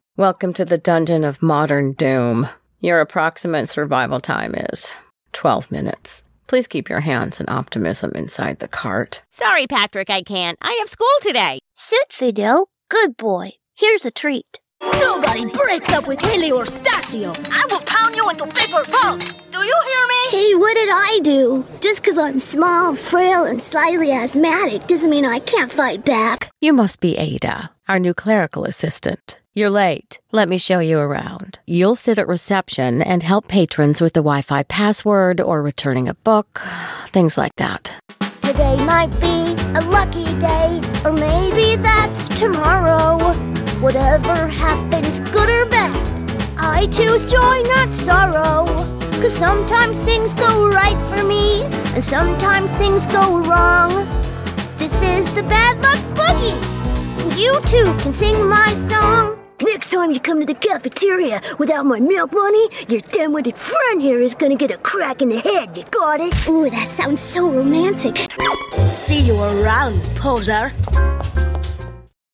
Theatre-trained actress, voiceover artist, and singer with over 10 years in VO, pro home studio, and quick turnaround.
Animation